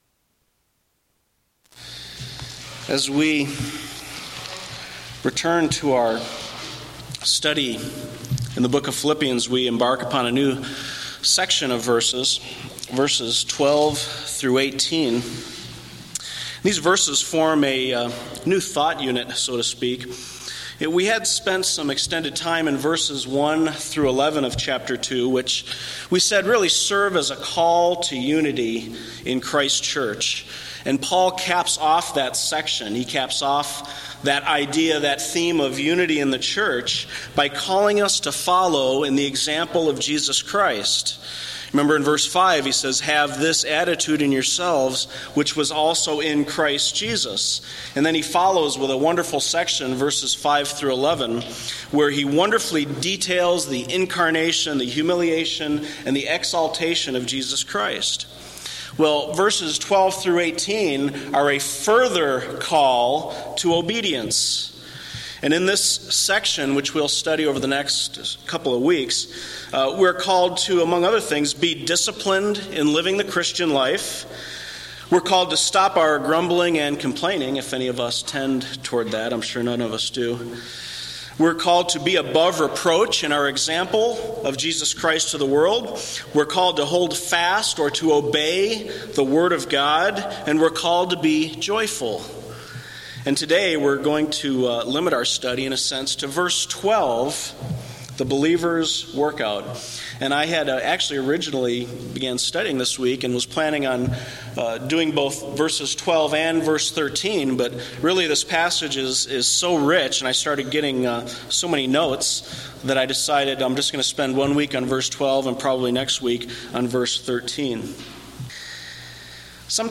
Sermon Central: Old and Raw Unedited Sermon MP3 Files
These are raw, unedited mp3 files of old messages, some very old (when I first began my ministry in the pulpit back in 1993 in Tempe, AZ).